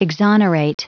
Prononciation du mot exonerate en anglais (fichier audio)
Prononciation du mot : exonerate